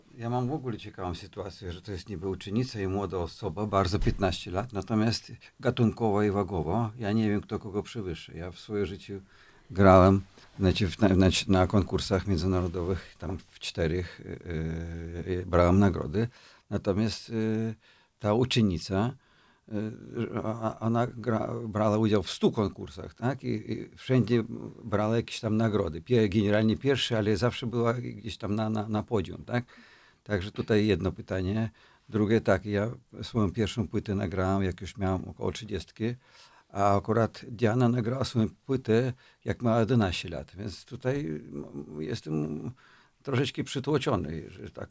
– Muszę ćwiczyć – dodaje i śmieje się skrzypek.